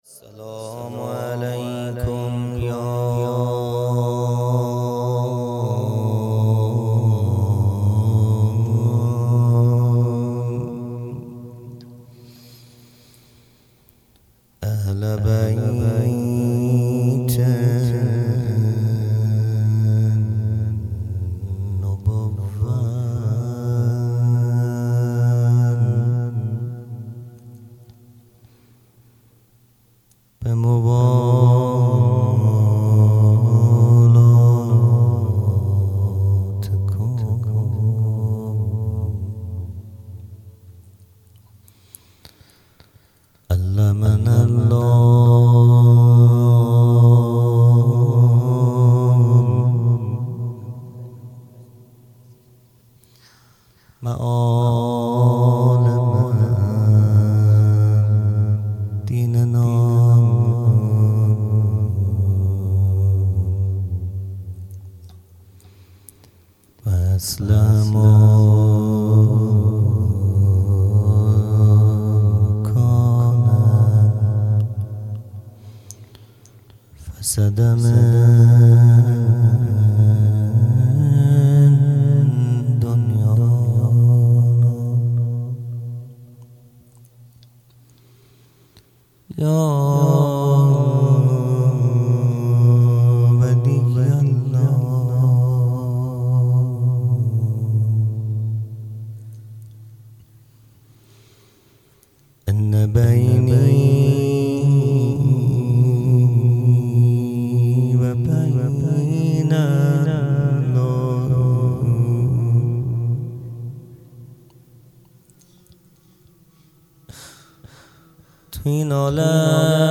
خیمه گاه - هیئت بچه های فاطمه (س) - روضه | دردی که گلسان مرا ریخته بر هم
جلسۀ هفتگی